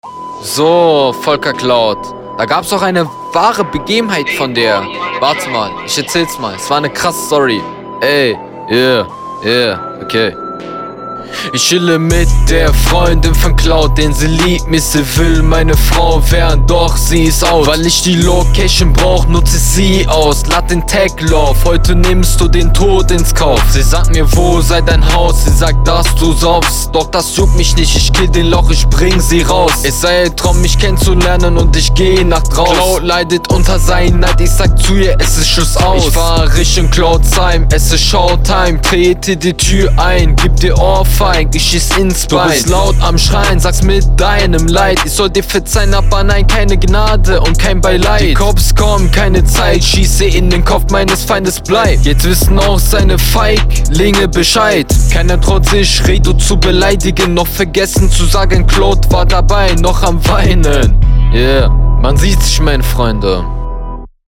Flow wieder ganz gut für bronze aber wieder auf Dauer zu monoton, textlich halt sehr …